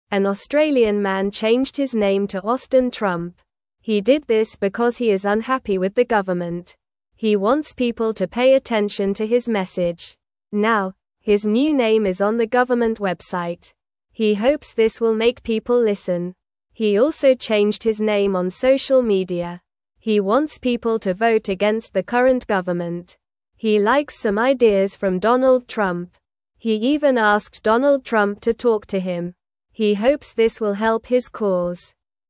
Normal
English-Newsroom-Beginner-NORMAL-Reading-Man-Changes-Name-to-Austin-Trump-for-Attention.mp3